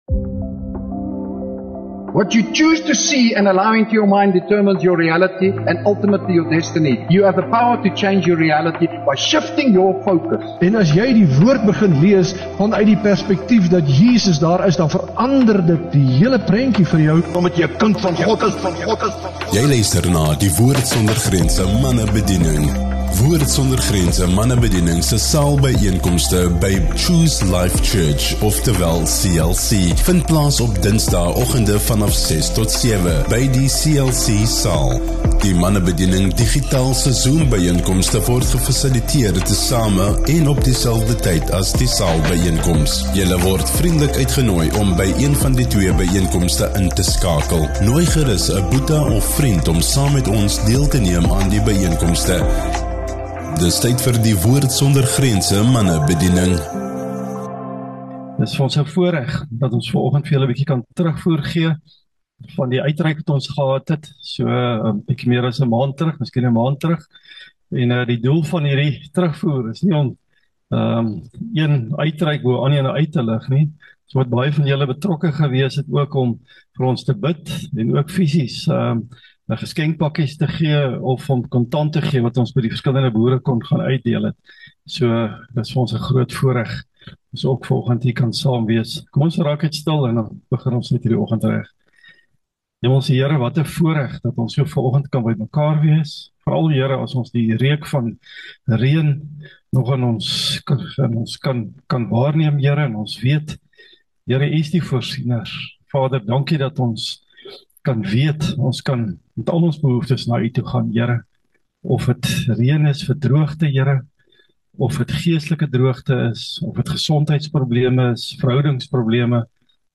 Woord Sonder Grense (WSG) Manne Bediening se byeenkoms by Choose Life Church op die 22 Okt 2024